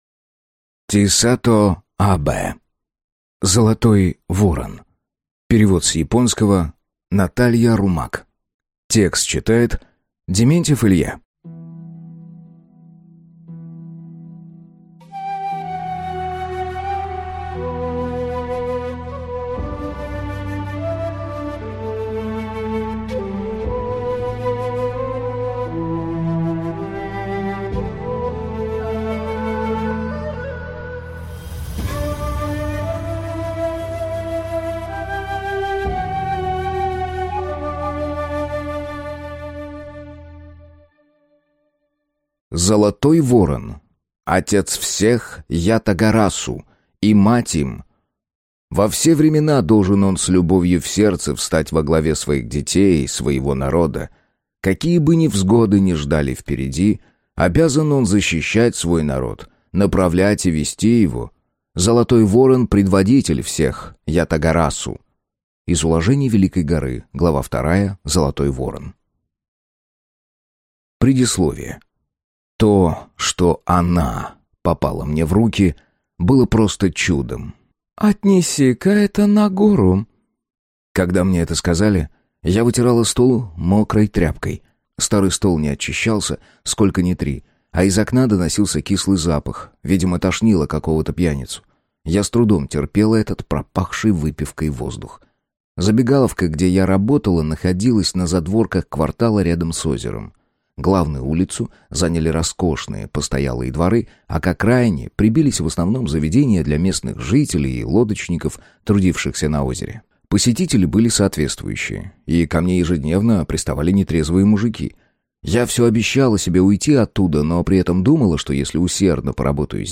Аудиокнига Золотой ворон | Библиотека аудиокниг